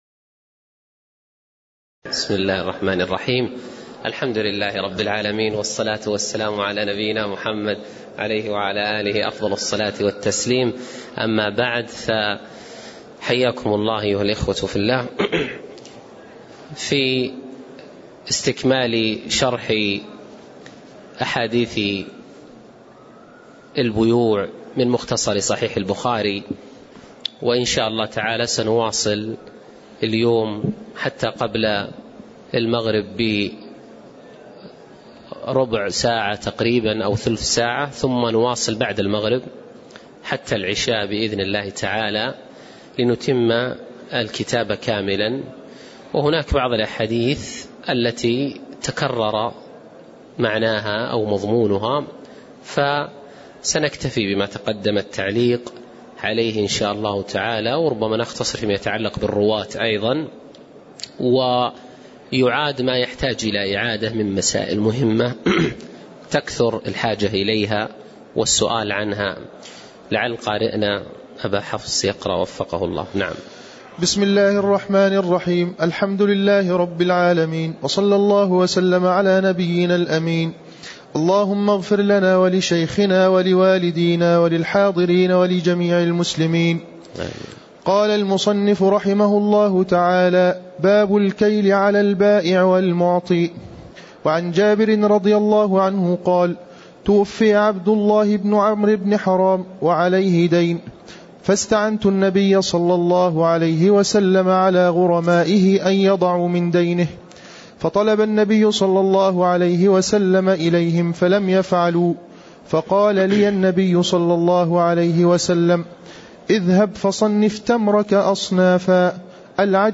تاريخ النشر ٦ جمادى الأولى ١٤٣٨ هـ المكان: المسجد النبوي الشيخ